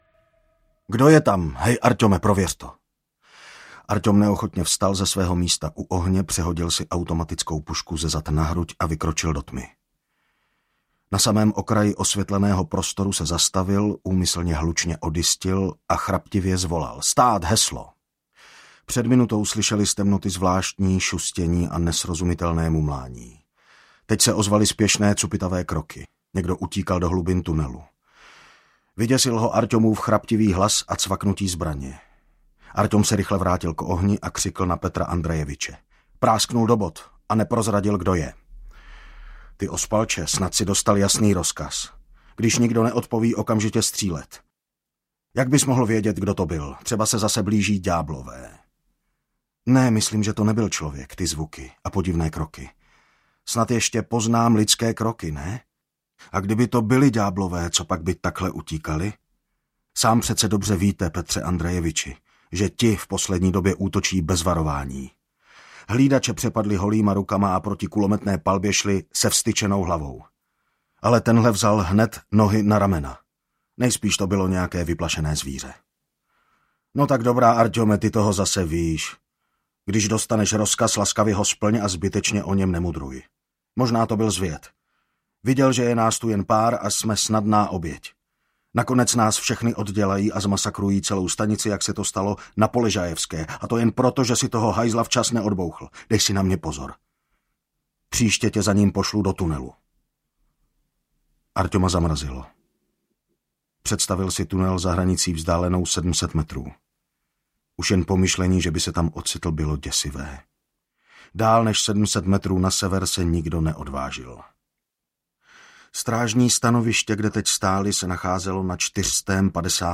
Metro 2033 audiokniha
Ukázka z knihy